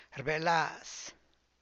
rbelaz[rbèe’lààa’z]